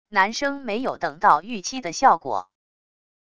男生没有等到预期的效果wav音频